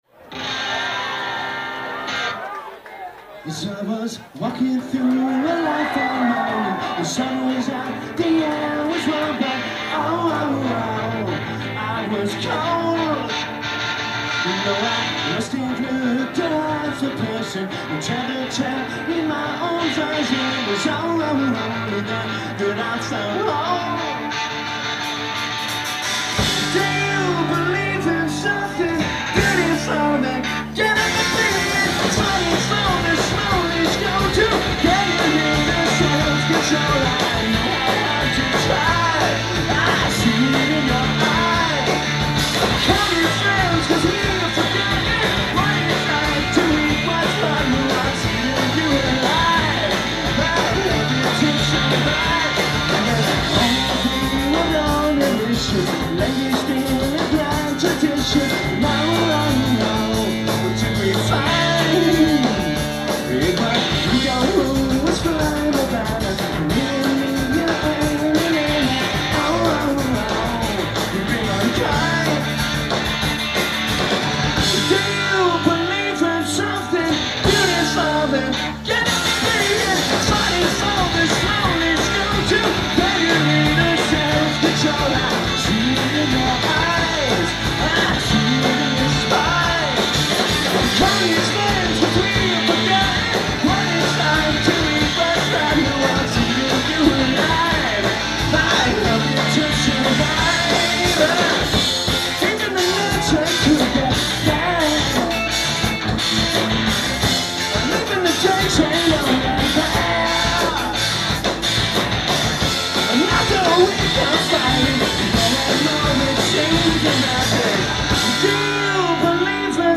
live at the Middle East, Cambridge, MA